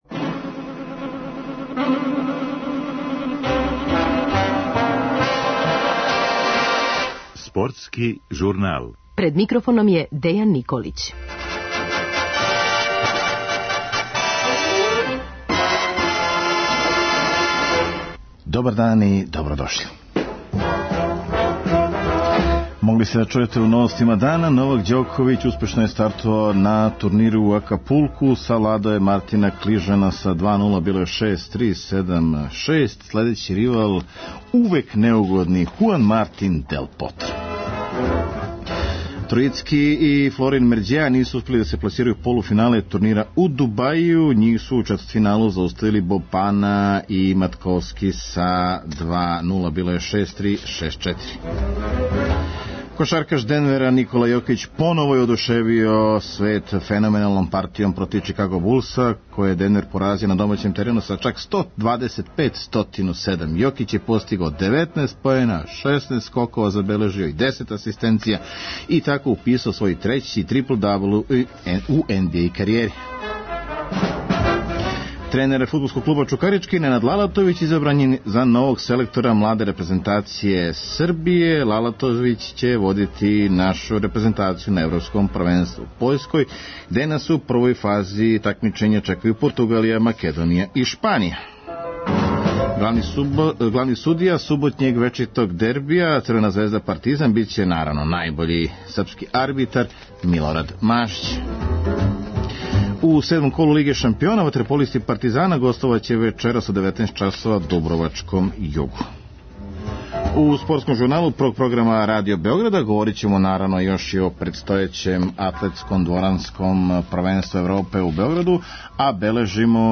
Чућете Николу Јокића који је у дресу Денвера забележио triple-double.